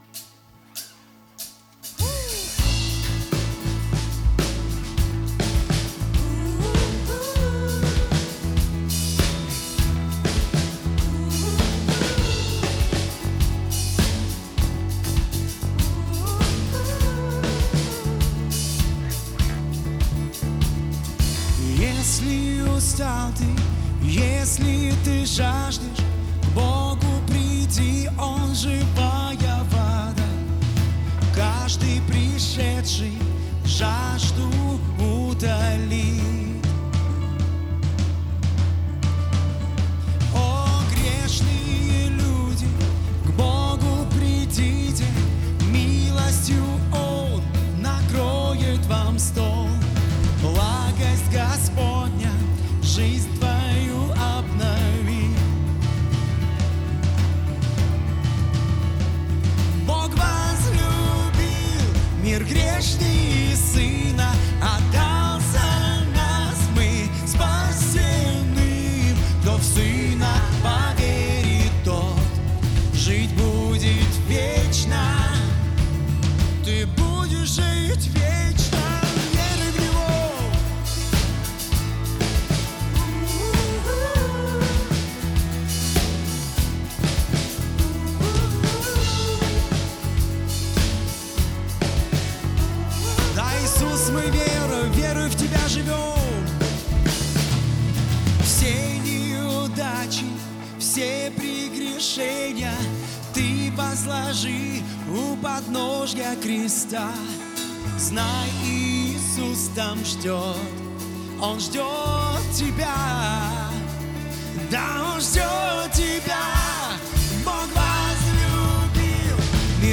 136 просмотров 66 прослушиваний 0 скачиваний BPM: 100